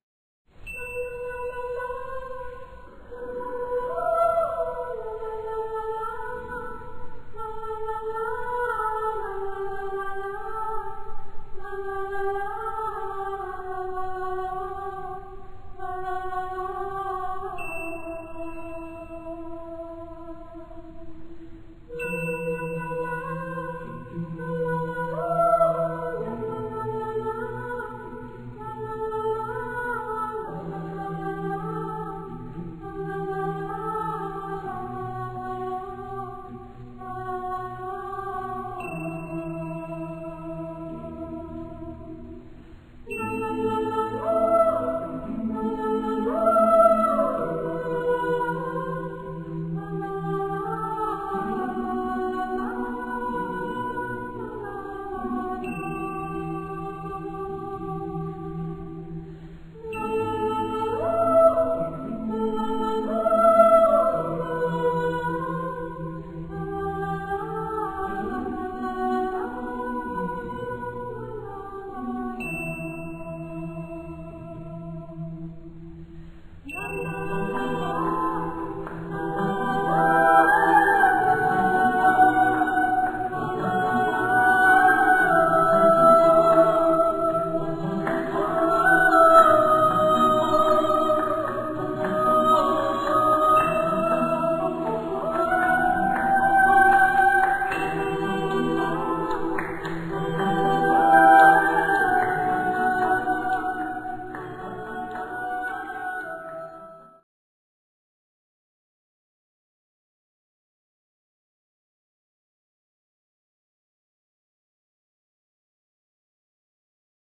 Accompaniment:      A Cappella
Language:      Nonsense syllables
Music Category:      Choral